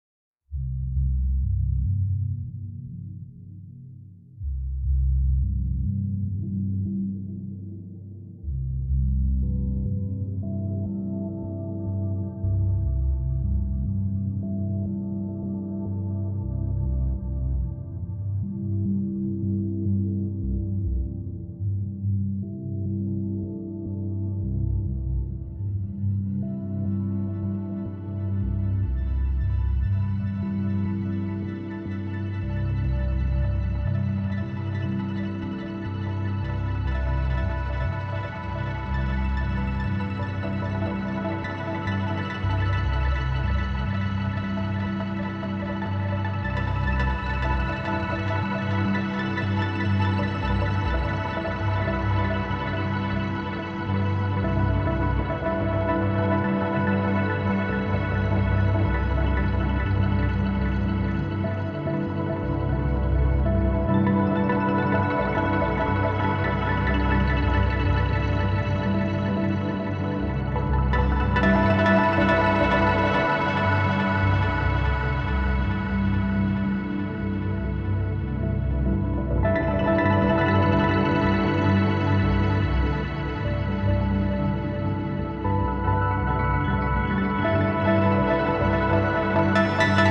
moog